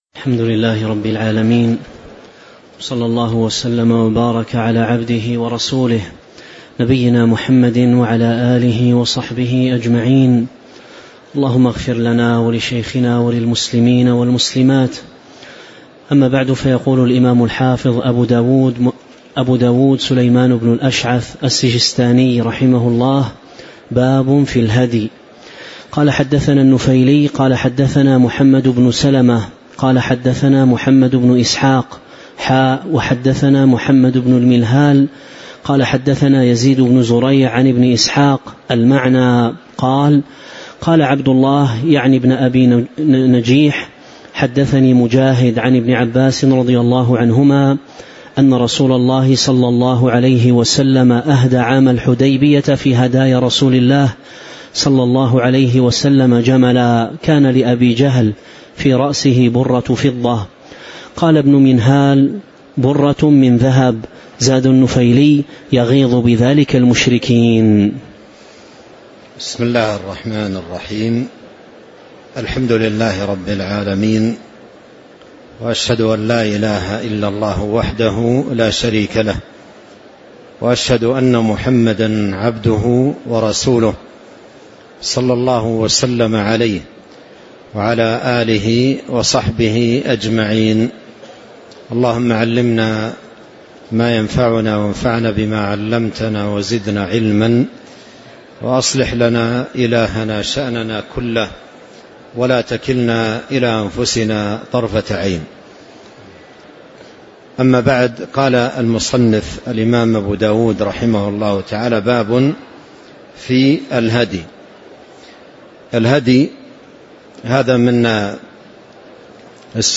تاريخ النشر ٢٣ ذو القعدة ١٤٤٦ المكان: المسجد النبوي الشيخ